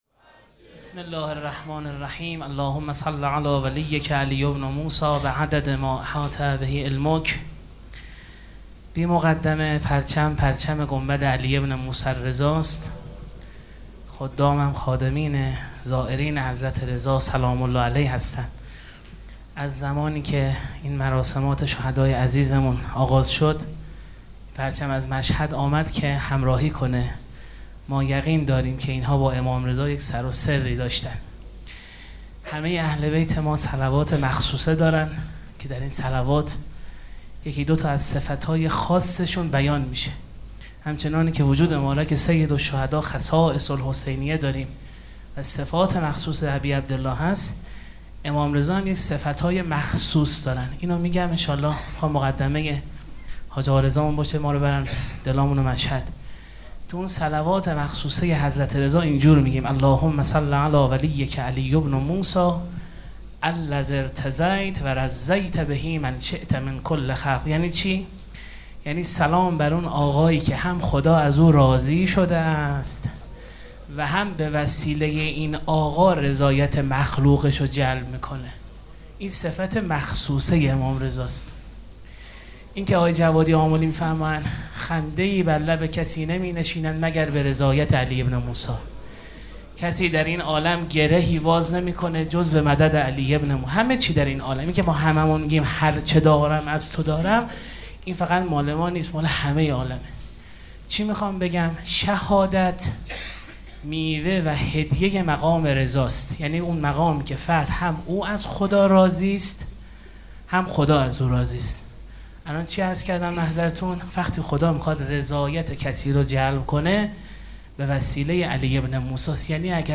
مراسم عزاداری شب چهارم محرم الحرام ۱۴۴۷
خادمین امام رضا(ع)